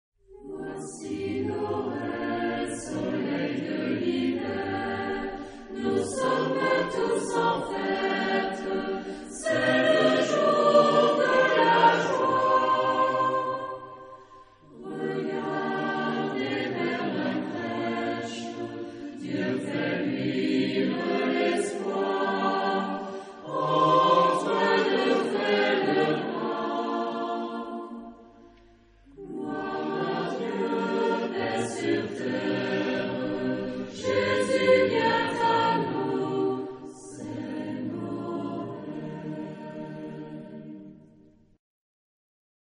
Genre-Stil-Form: Weihnachtslied ; geistlich
Chorgattung: SATB  (4 gemischter Chor Stimmen )
Tonart(en): Es-Dur